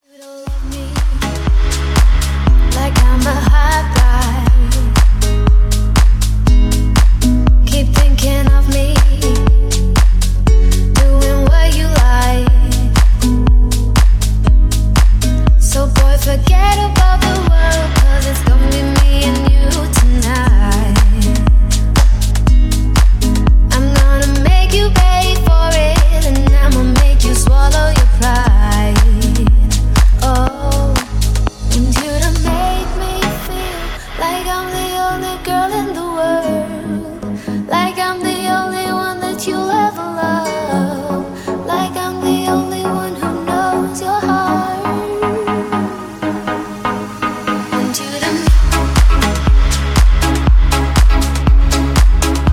гитара
deep house
чувственные
Cover Mix